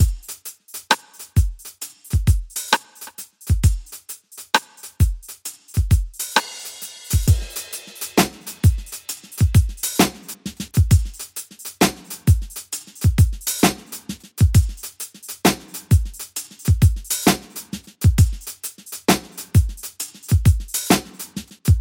HALF TIME SHUFFLE Straight 2 Progression 132 BPM
描述：与"Rim Shot Half Time Shuffle"相同，但在用Rim Shot做了一个小引子后，用小鼓和幽灵音符。
标签： 132 bpm Soul Loops Drum Loops 3.67 MB wav Key : Unknown
声道立体声